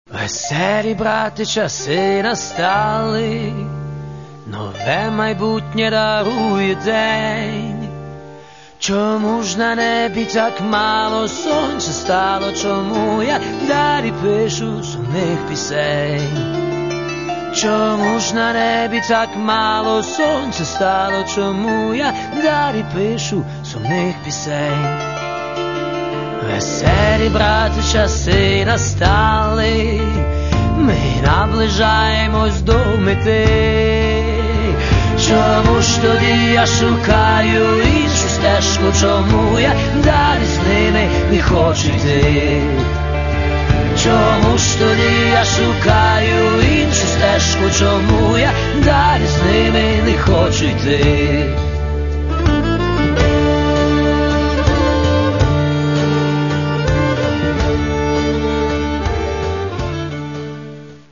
Рок та альтернатива